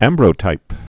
(ămbrō-tīp)